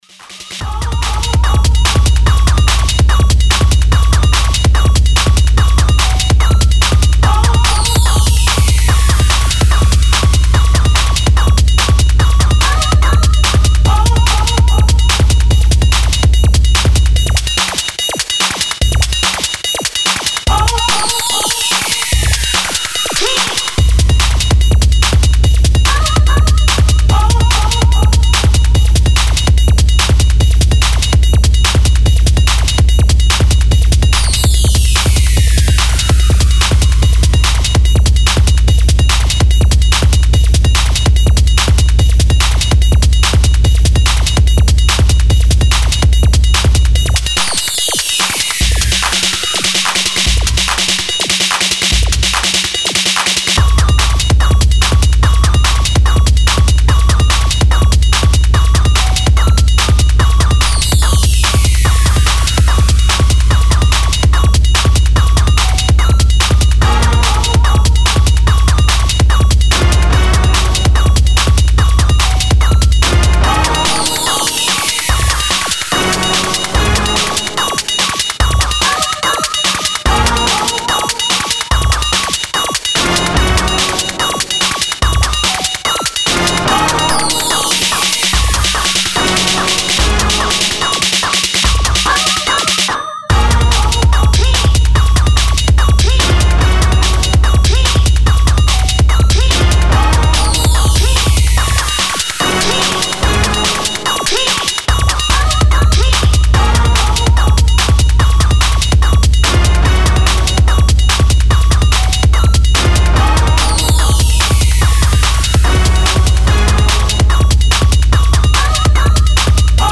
Style: Ghetto Techno